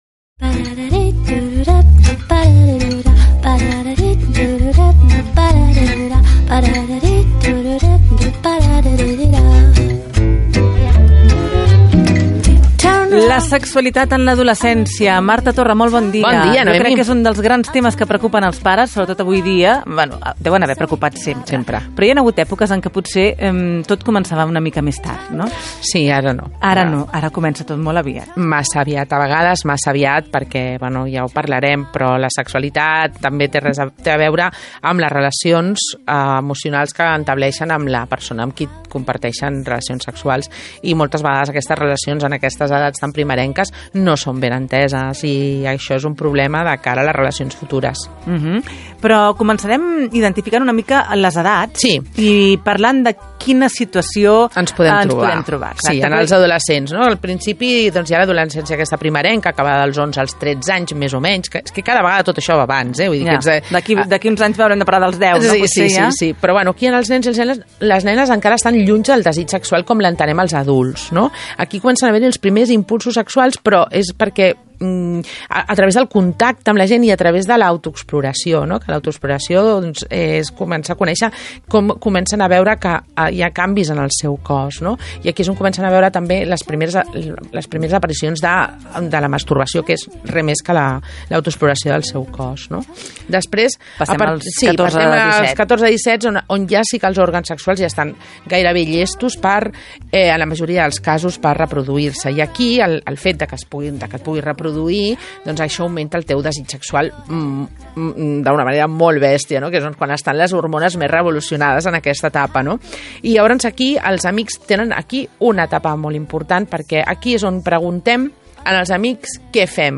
La primera pedra: entrevista Laura Rosel - RAC1, 2017